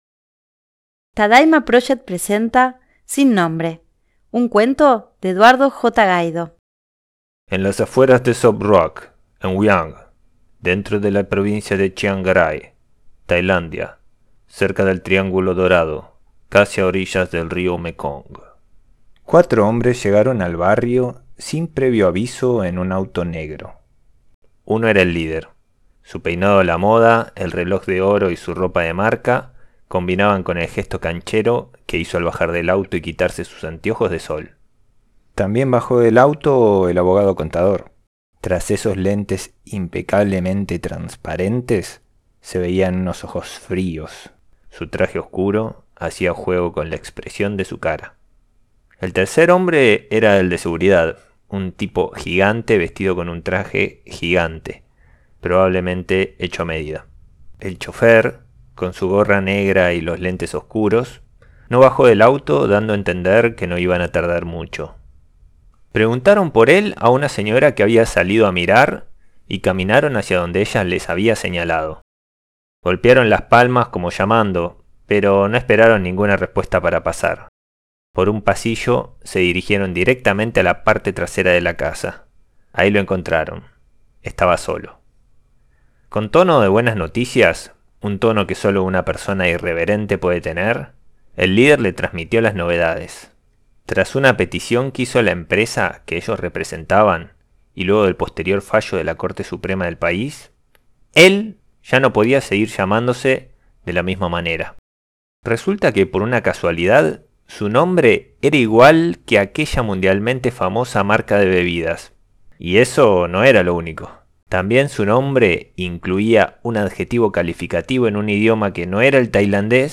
Cuento corto #9